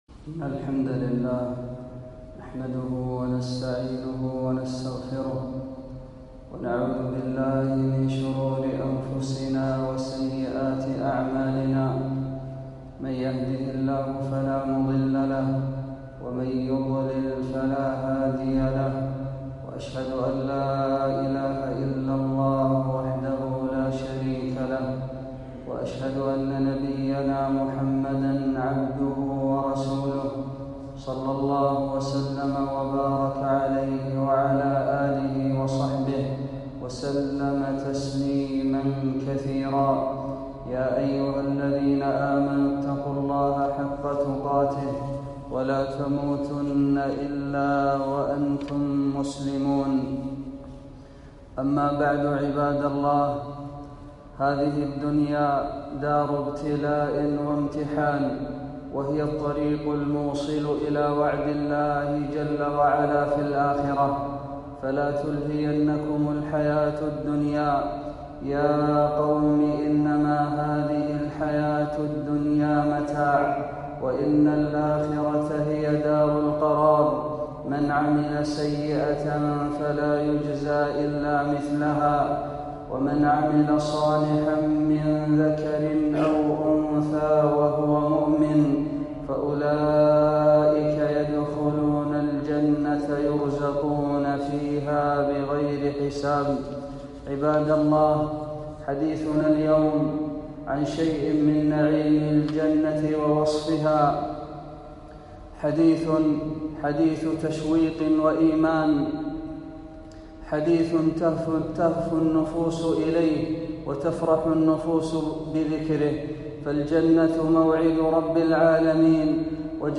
خطبة - وصف الجنة